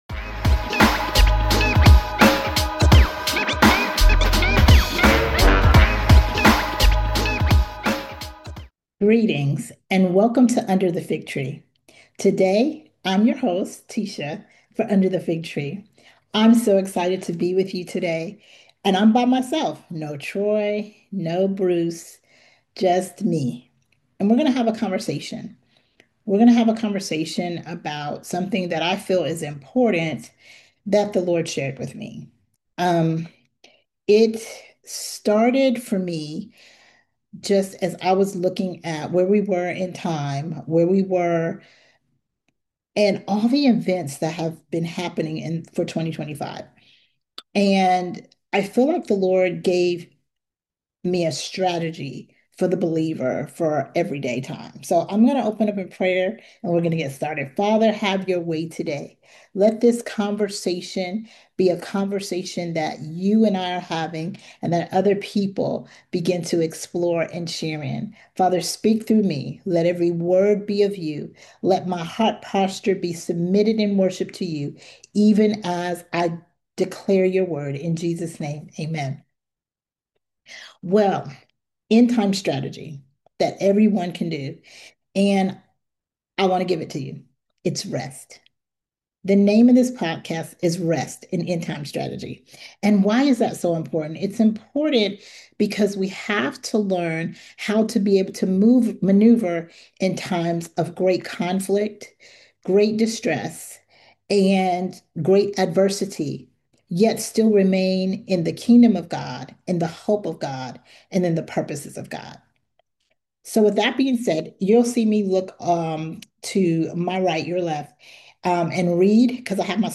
Just two guys that love Jesus and want everyone else to love Him too. We create dialogue centered around the Word of God with the intent to edify and encourage the Body of Christ at large.